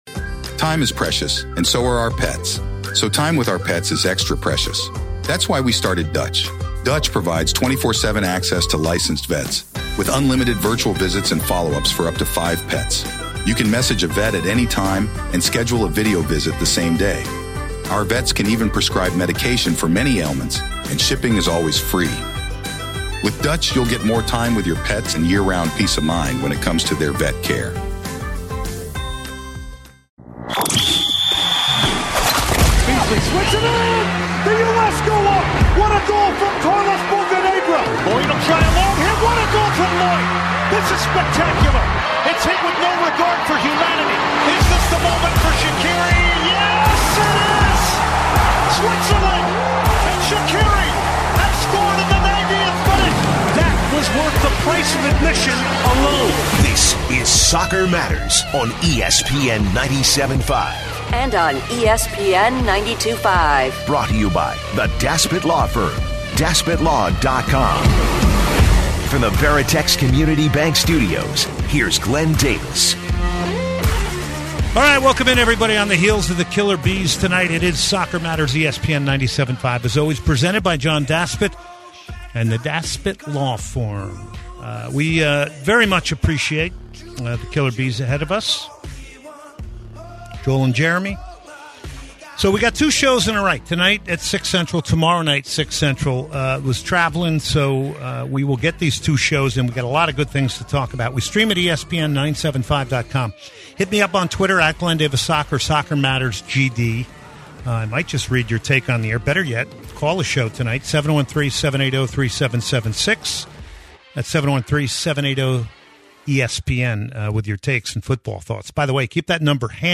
he joins the show LIVE.